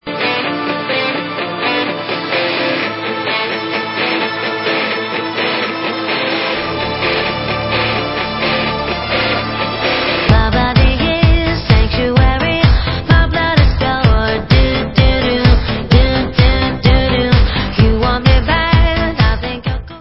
sledovat novinky v kategorii Dance
Pop